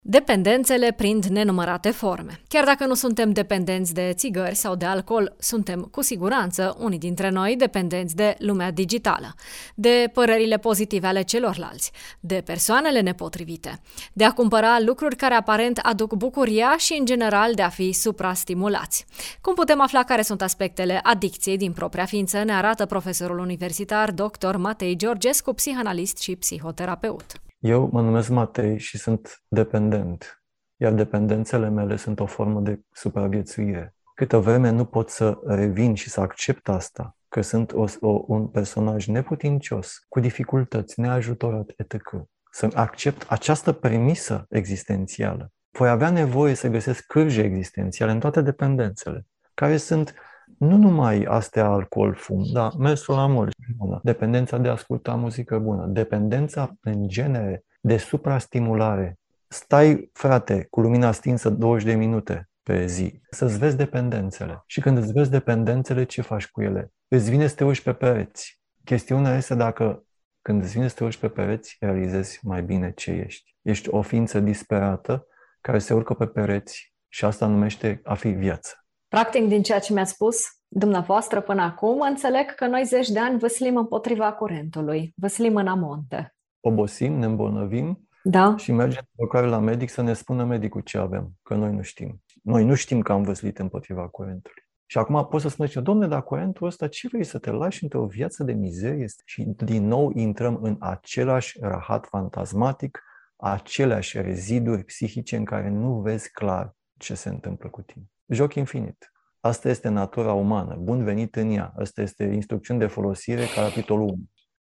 psihanalist, psihoterapeut: